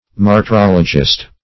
Search Result for " martyrologist" : The Collaborative International Dictionary of English v.0.48: Martyrologist \Mar`tyr*ol"o*gist\, n. [Cf. F. martyrologiste.] A writer of martyrology; an historian of martyrs.